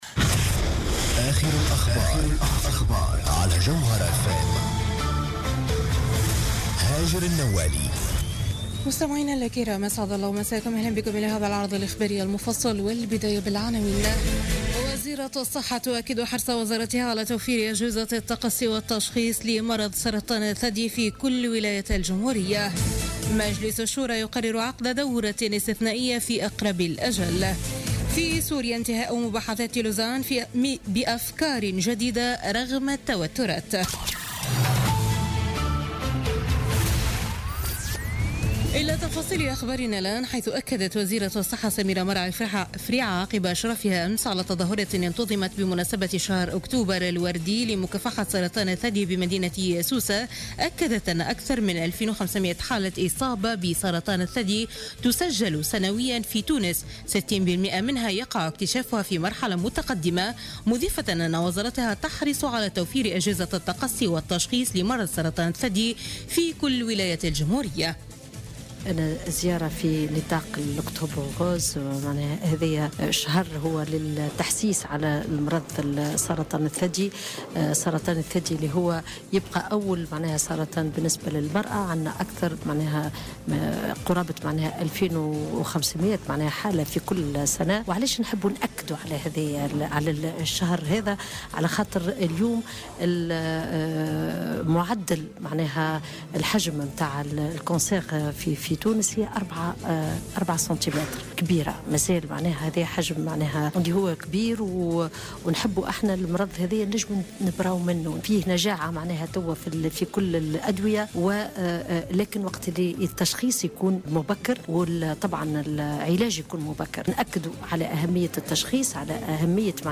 نشرة أخبار منتصف الليل ليوم الأحد 16 أكتوبر 2016